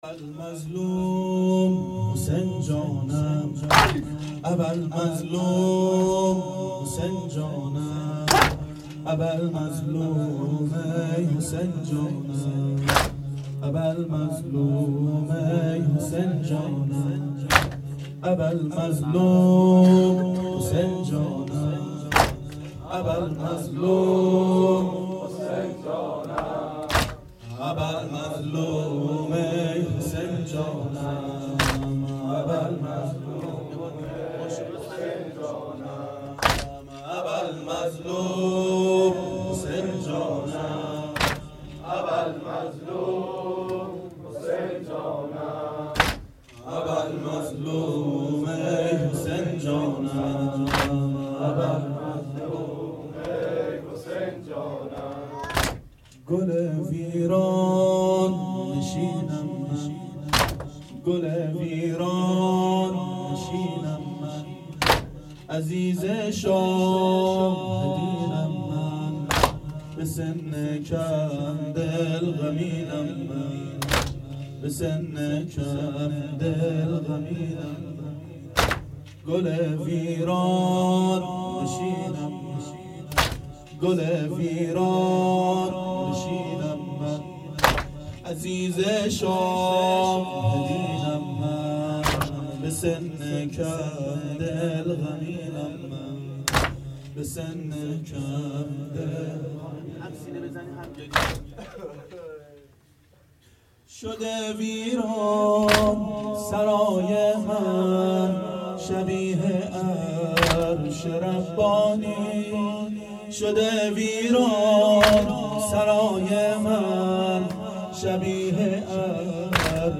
• شب سوم محرم 92 هیأت عاشقان اباالفضل علیه السلام منارجنبان